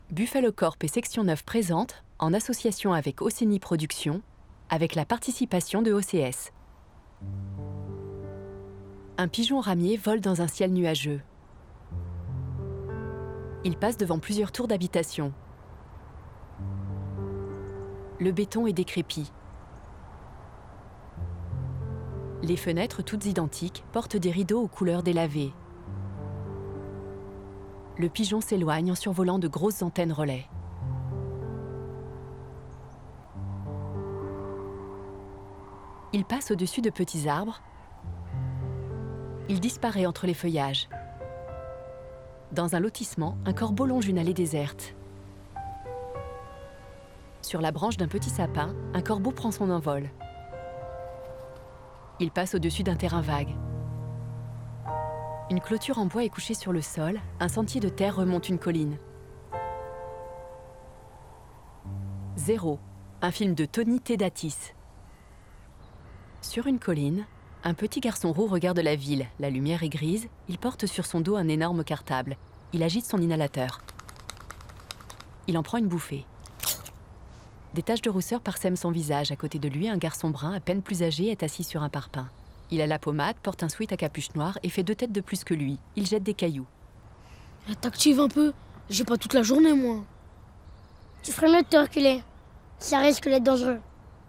Audiodescription : Zéro